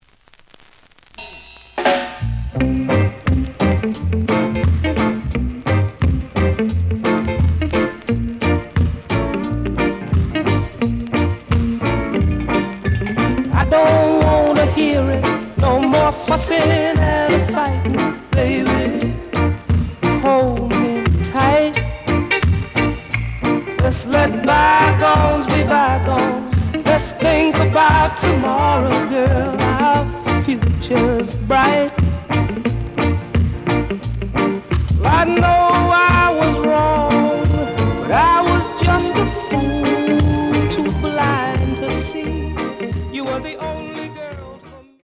The audio sample is recorded from the actual item.
●Format: 7 inch
●Genre: Reggae